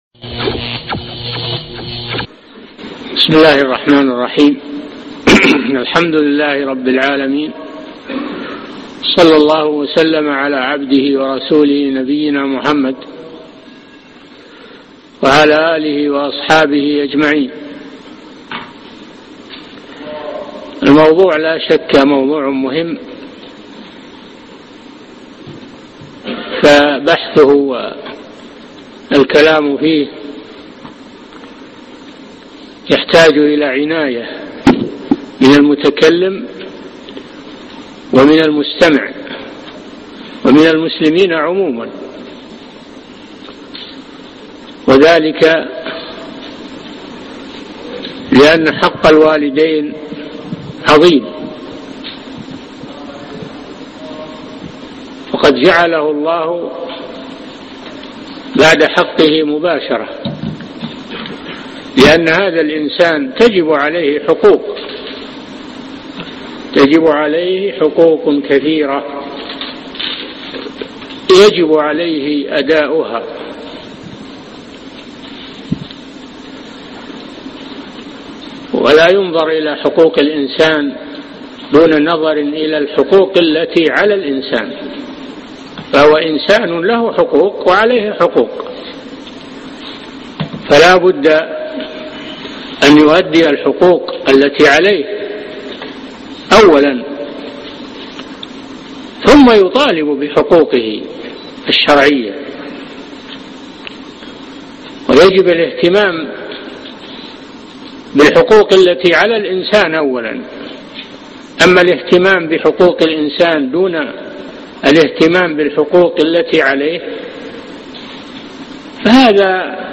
بر الوالدين والتحذير من العقوق (27/12/2011) محاضرة اليوم - الشيخ صالح بن فوزان الفوازان